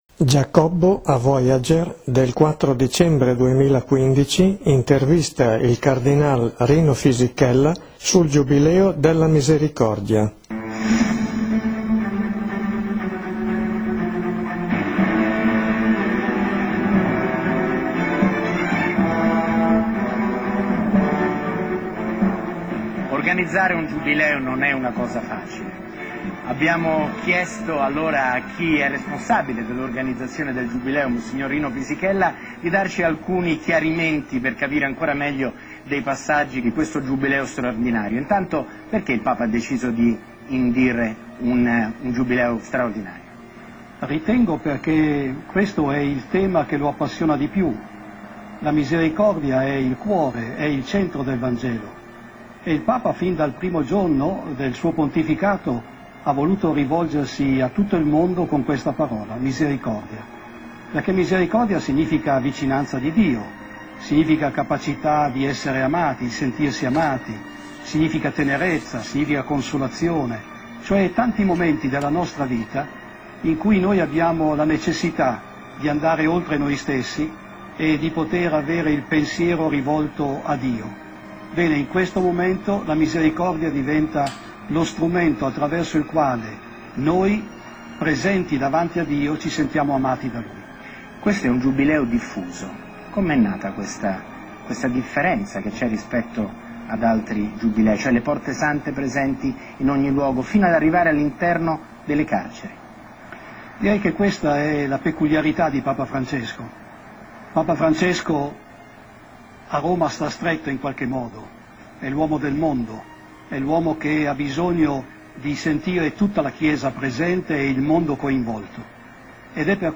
Chi desidera comprendere il significato di indulgenza può ascoltare un'intervista fatta mons. Rino Fisichella nel dicembre scorso.